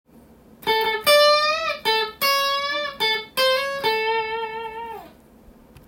tab譜のkeyはAにしてみました。
Aメジャーペンタトニックスケール